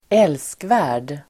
Uttal: [²'el:skvä:r_d]